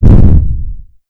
missile-explo.wav